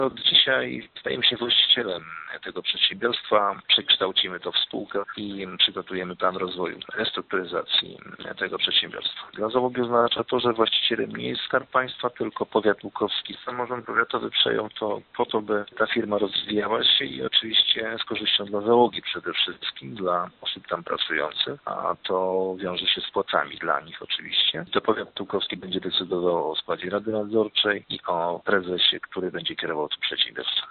„Oznacza to, że przedsiębiorstwo stało się właścicielem 262 tysięcy akcji o nominale 10 złotych każda” - powiedział Informacyjnej Agencji Samorządowej tuż po podpisaniu dokumentów Starosta Łukowski Longin Kajka: